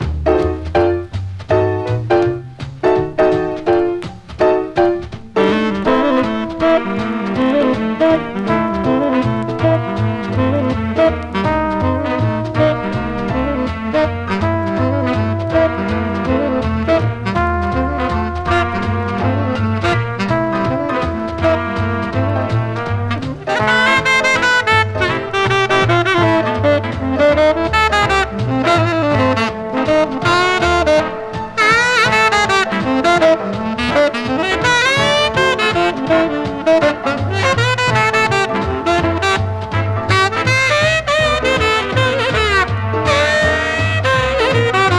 Jazz, Rhythm & Blues, Jump　Sweden　12inchレコード　33rpm　Mono